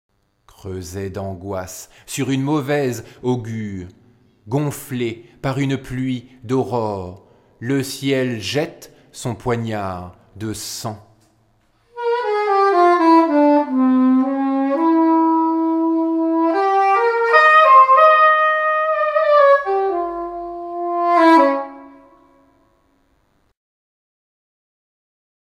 improvisation saxophone soprano